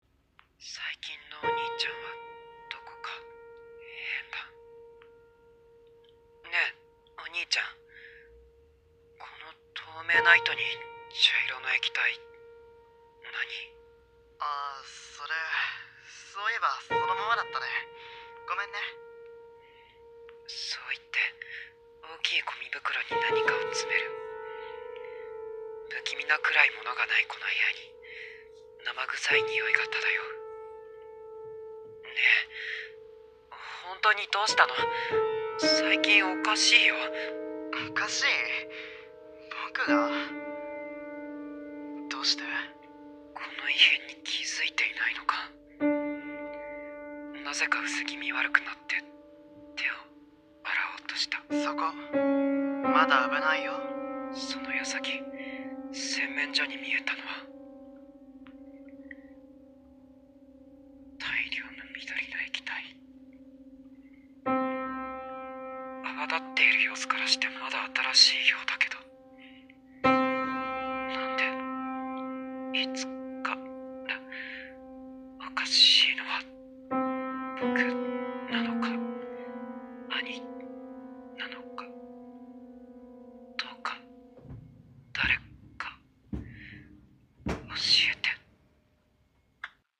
【ホラー声劇】「ヘンカ」